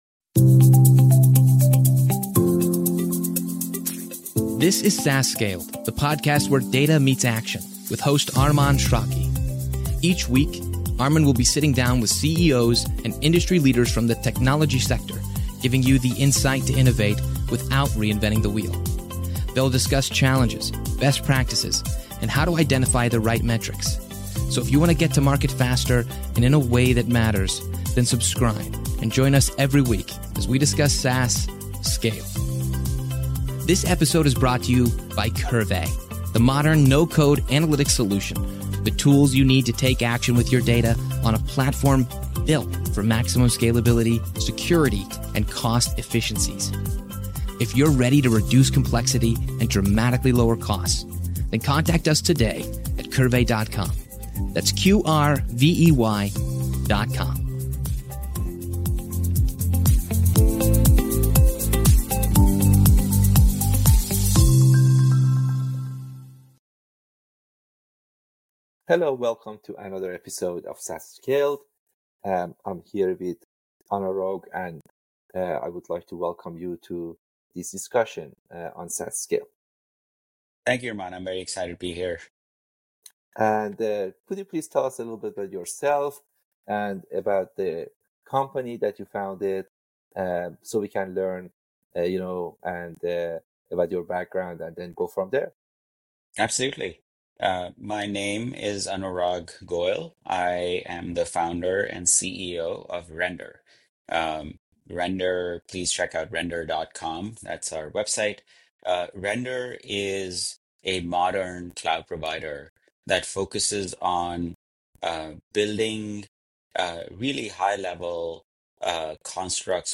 SaaS Scaled - Interviews about SaaS Startups, Analytics, & Operations